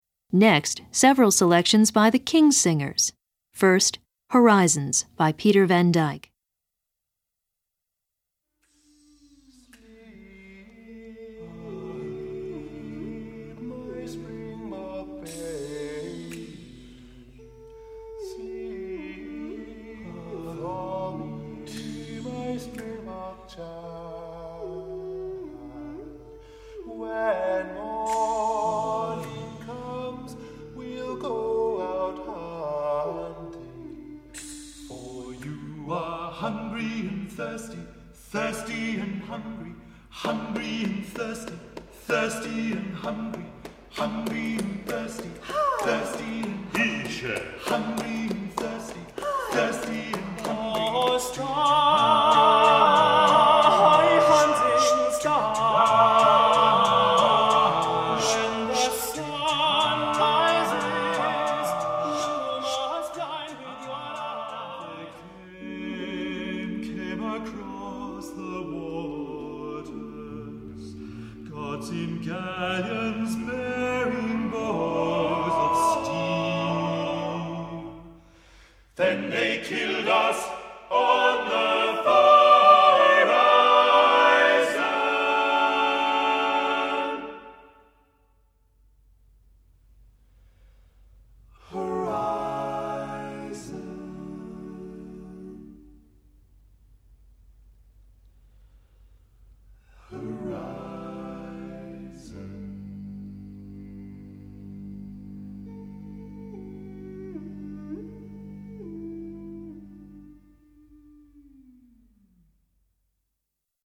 Voicing: SATTBB